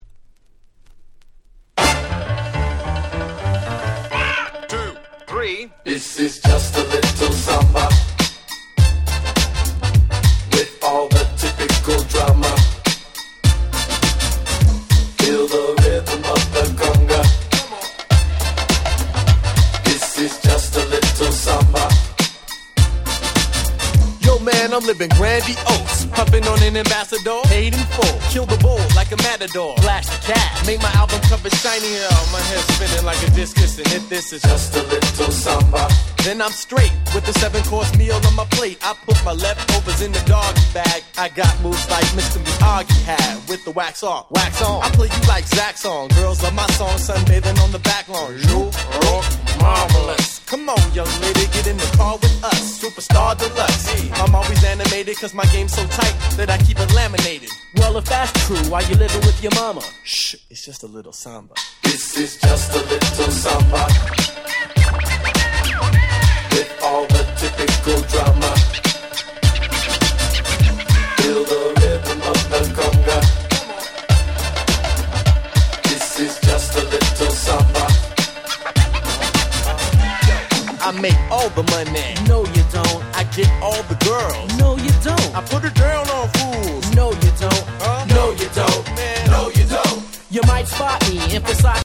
Boom Bap